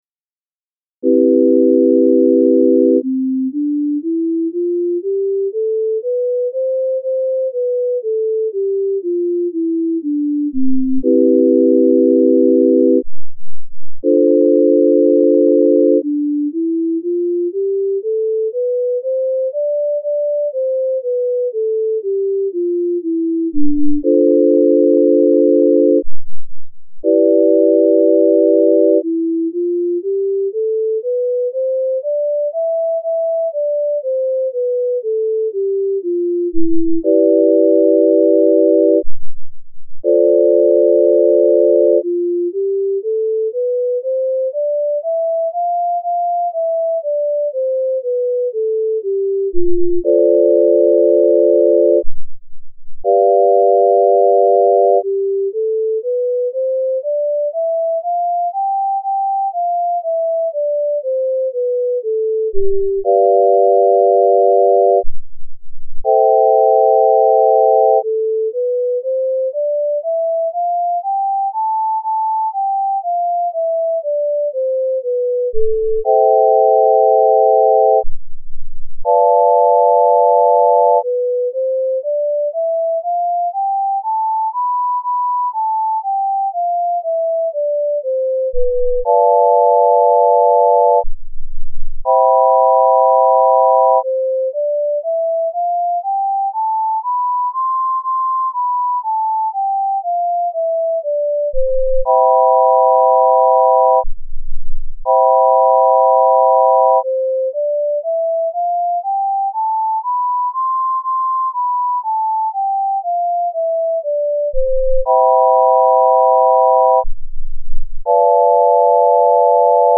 C-Major Scale Using the Pythagorean Scale